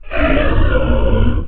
creature-sound